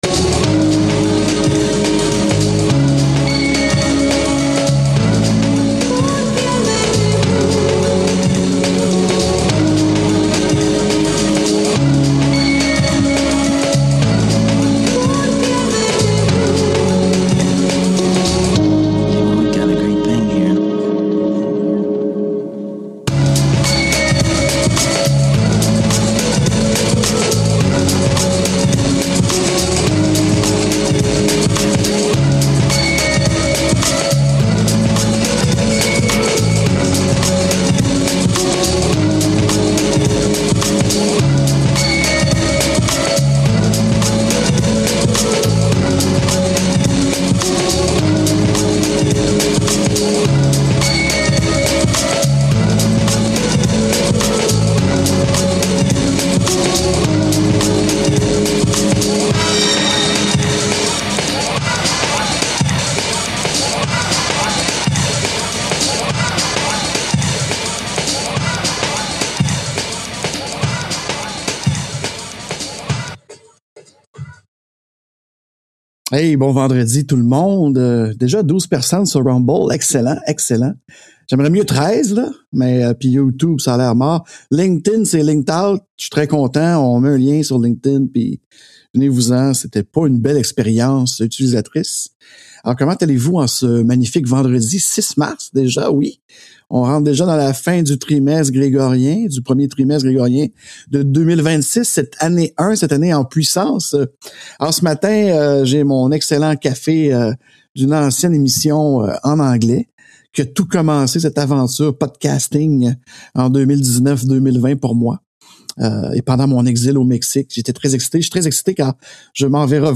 Joins toi à moi avec un bon café, LIVE le Premier Vendredi du mois entre 9h ou Midi, heure de l’Est.